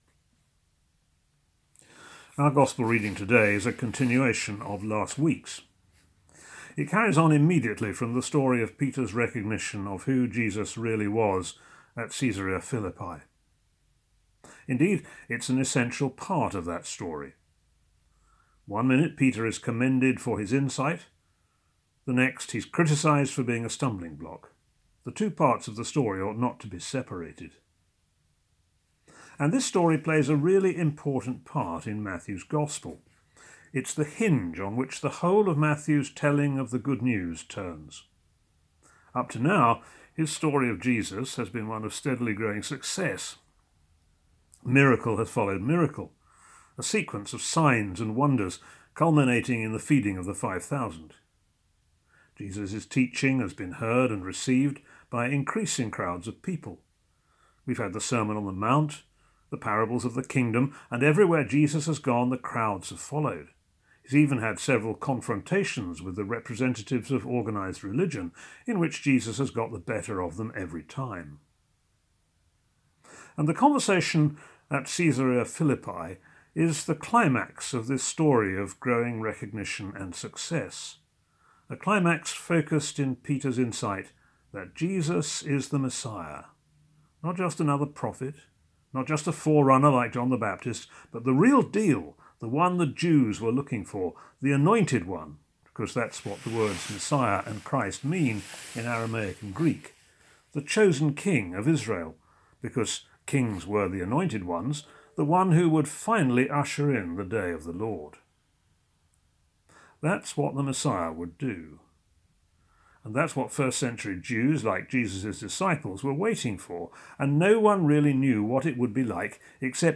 A sermon on Matthew 16:24 for a Borders Centre of Mission joint service on 30th August 2020